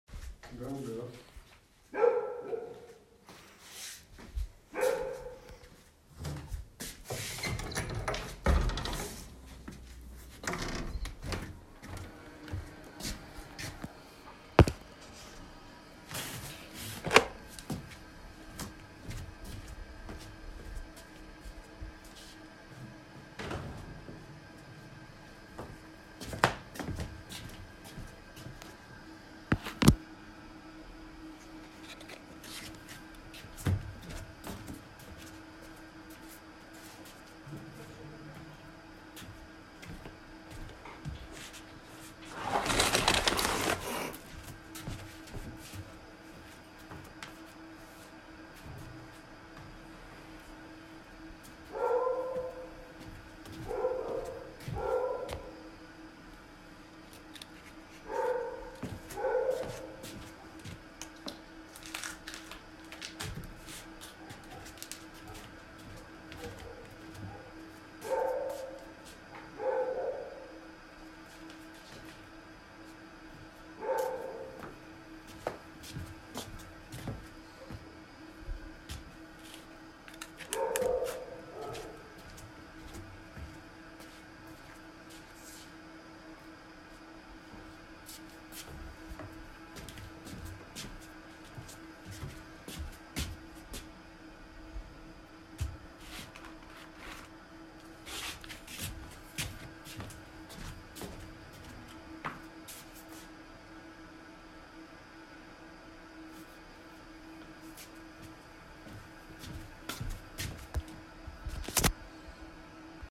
A woman went out onto the balcony of her new apartment to rearrange the flowers. She is surrounded by the rare sounds of the street in an emptying town within the 'remoteness' of Armenian highlands.
Part of the Migration Sounds project, the world’s first collection of the sounds of human migration.